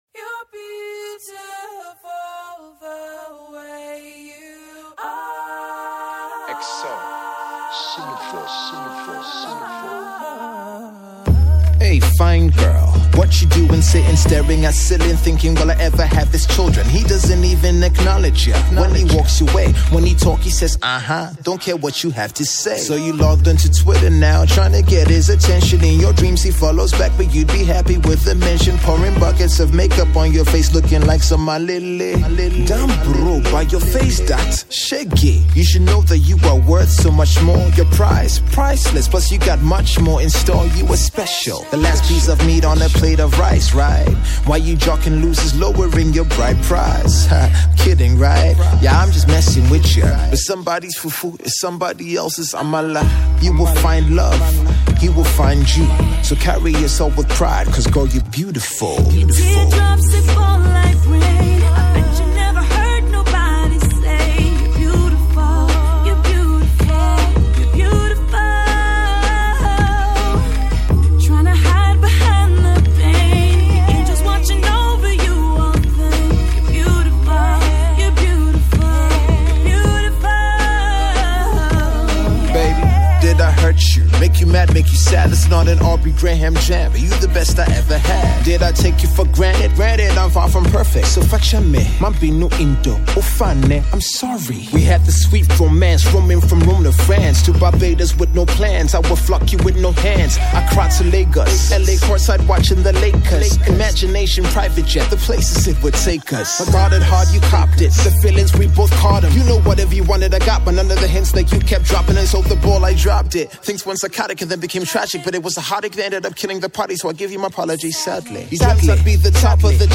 drops hot bars